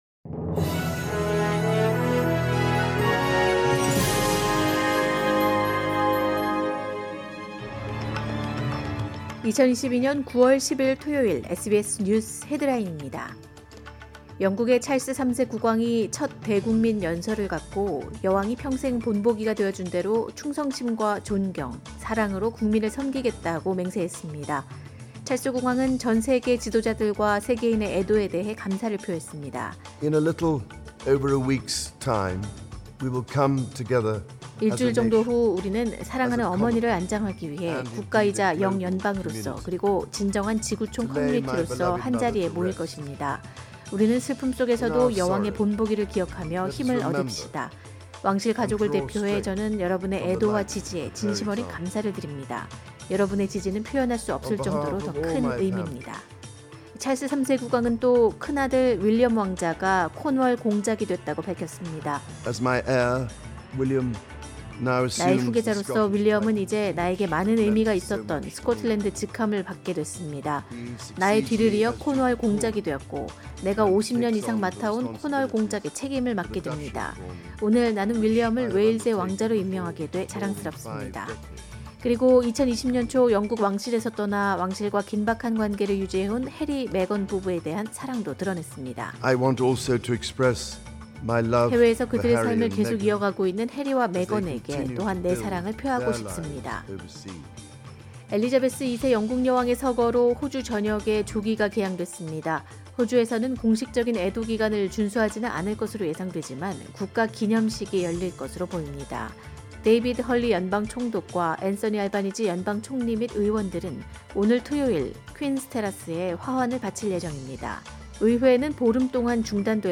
2022년 9월 10일 토요일 SBS 한국어 간추린 주요 뉴스입니다.